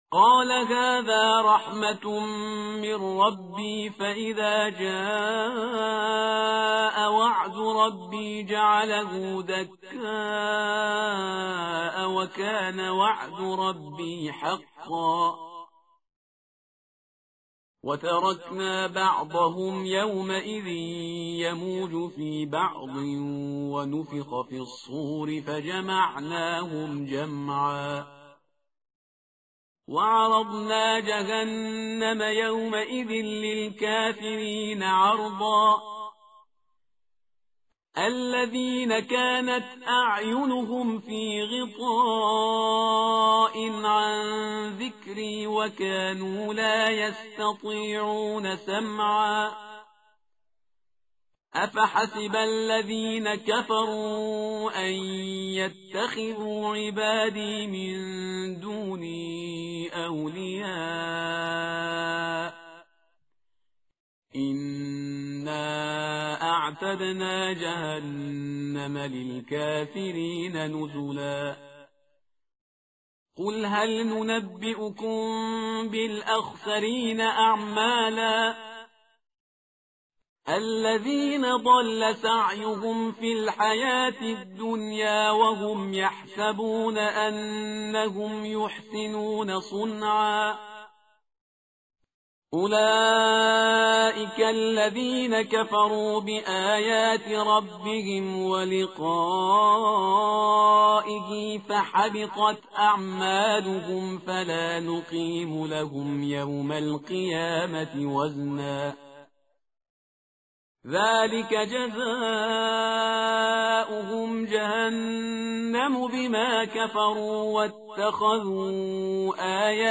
متن قرآن همراه باتلاوت قرآن و ترجمه
tartil_parhizgar_page_304.mp3